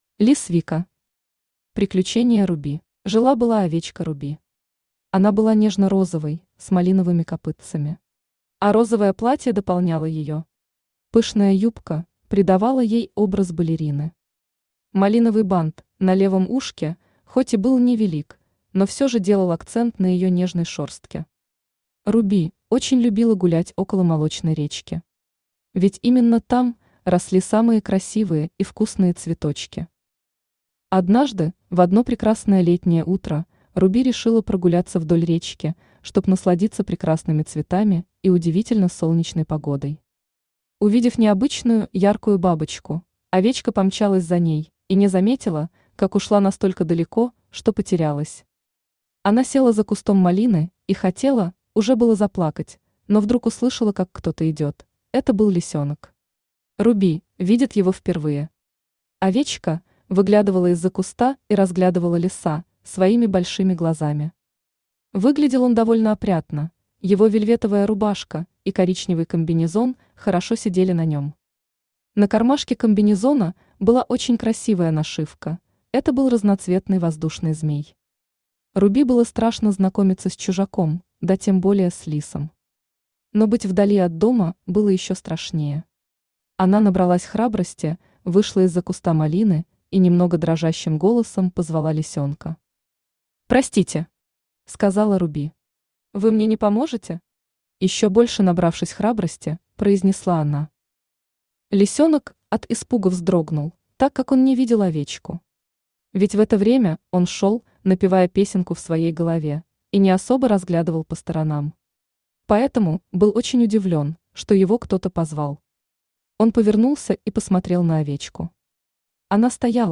Аудиокнига Приключение Руби | Библиотека аудиокниг